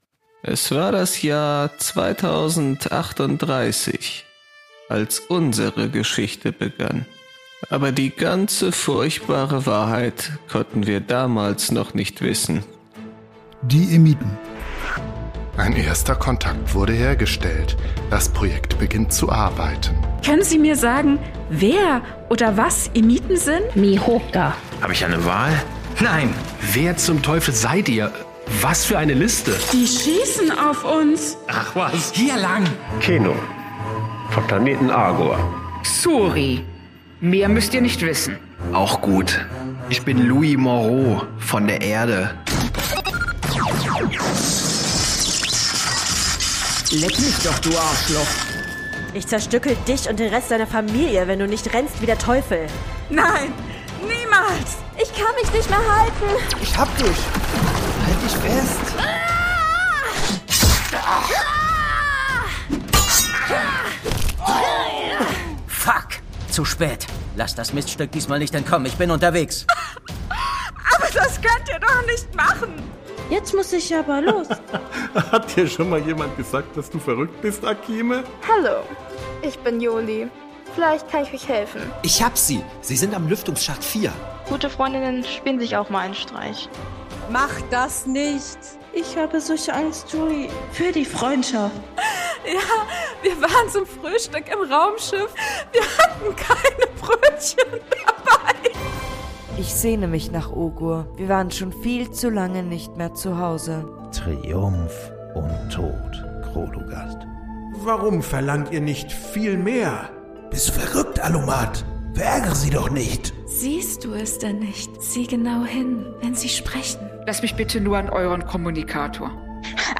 Das Hörspiel der Podcaster